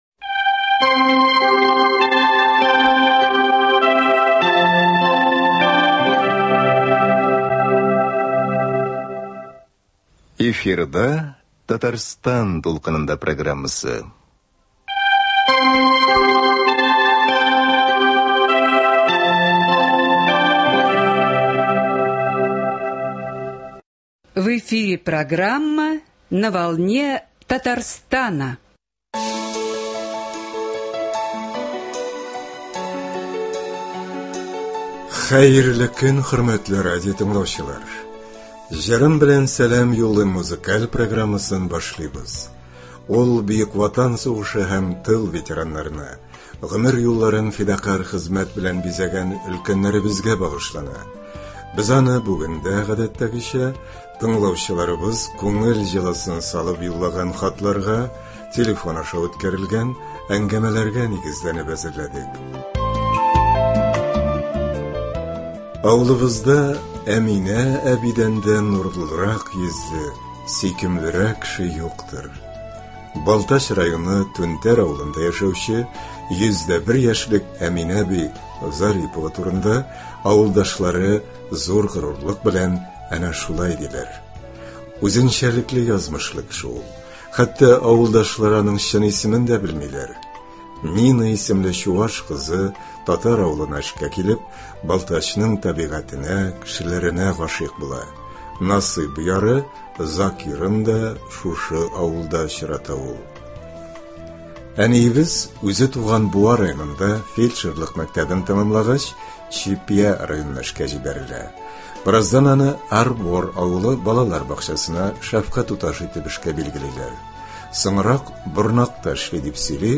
Ветераннар өчен музыкаль программа.